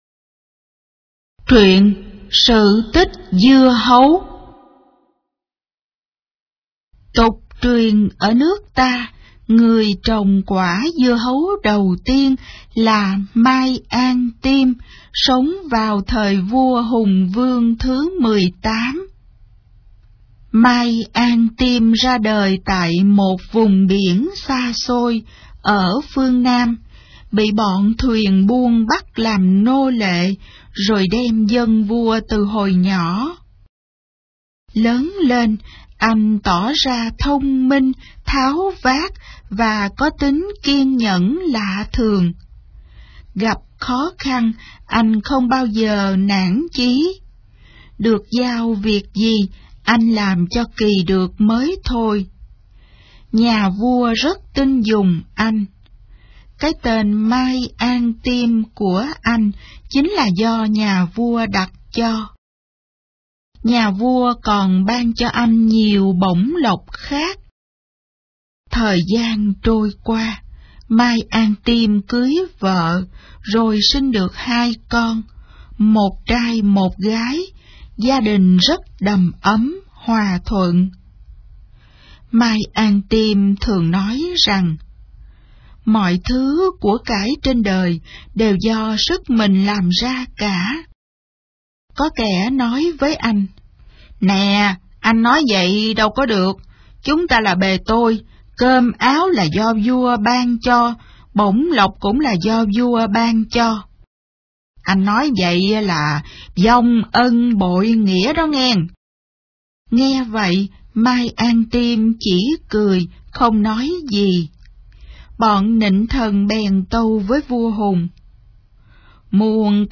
Sách nói | Sự tích Dưa hấu